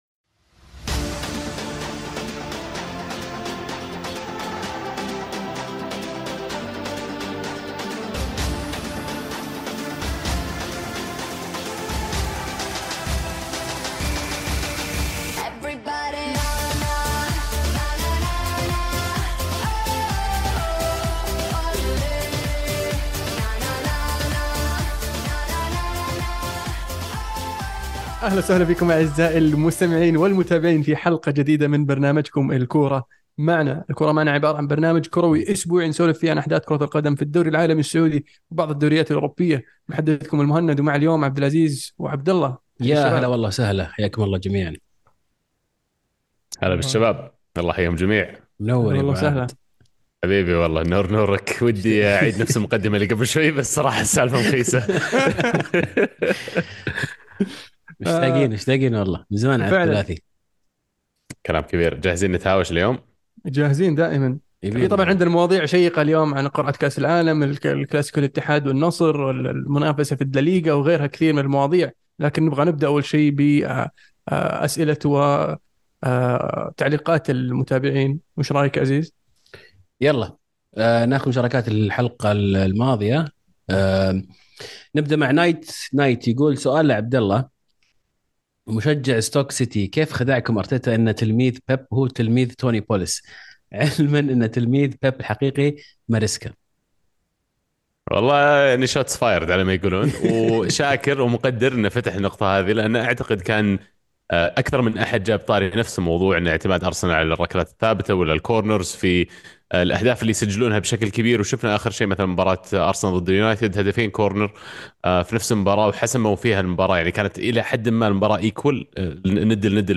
بودكاست "الكورة معنا" برنامج صوتي كروي اسبوعي من تقديم شباب عاشقين لكرة القدم، يناقشون فيه اهم الاحداث الكروية العالمية والمحلية خلال الأسبوع بعيد عن الرسمية.